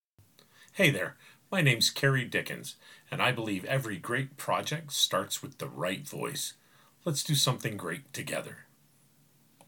I'm a middle aged American male with a dynamic voice and a lot of heart.
Conversational Demo
Slight Southern. Some SW England. Some upper class London
Conversational Voice Sample_0.mp3